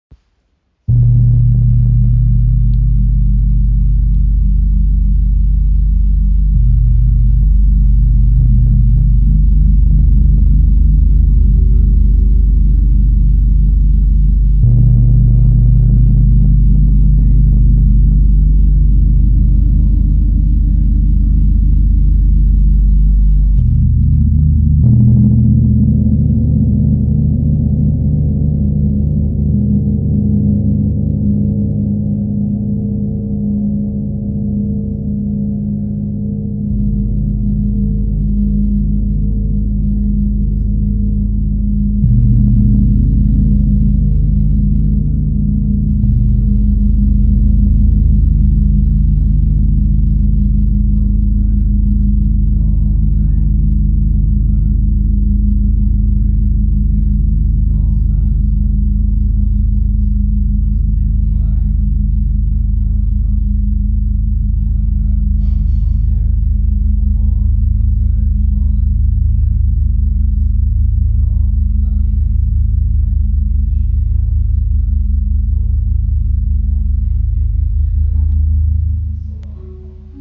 Tam-Tam Gong Ø 120 cm im Raven-Spirit WebShop • Raven Spirit
Klangbeispiel
Bei diesem Exemplar handelt es sich um einen erfahrenen Gong den wir aus einer Sammlung übernehmen durften. In der Regel haben Gongs, die über Jahrezehnte gewürdigt wurden einen besonders schönen Klangcharakter.